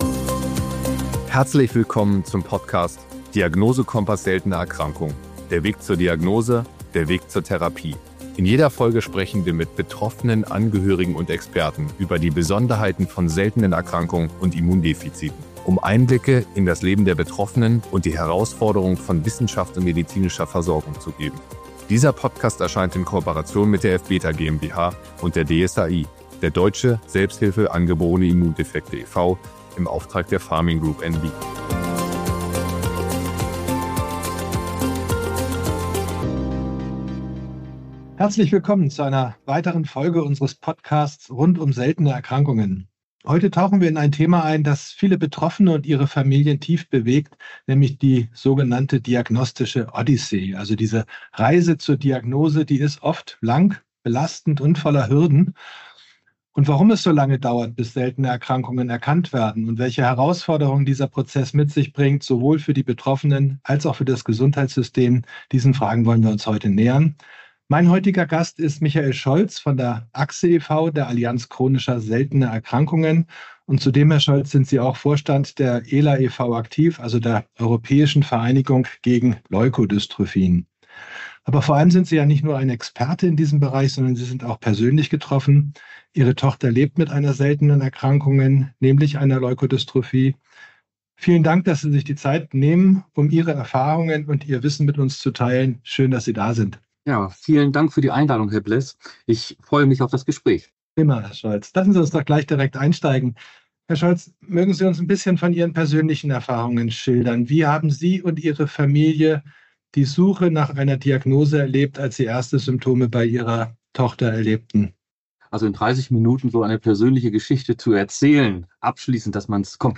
Zugleich diskutieren wir, welche Lösungsansätze es gibt – von KI-gestützter Diagnostik bis hin zur Bedeutung des Neugeborenen-Screenings. Das Gespräch